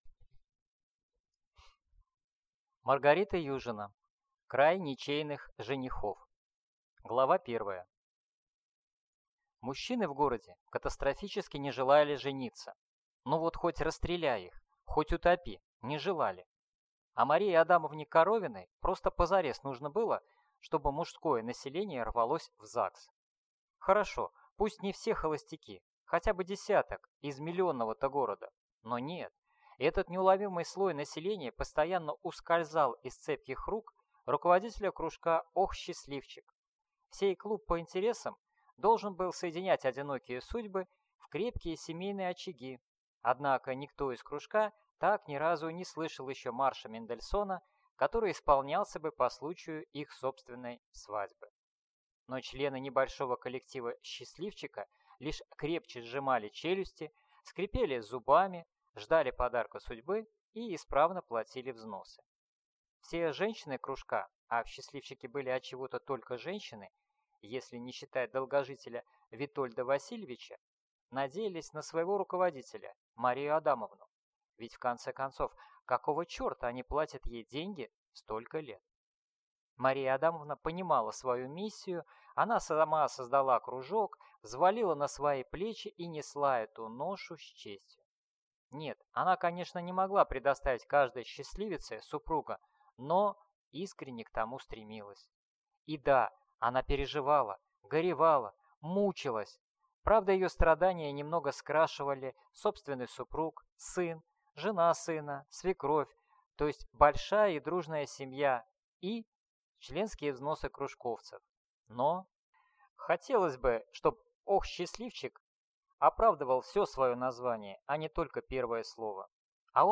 Аудиокнига Край ничейных женихов | Библиотека аудиокниг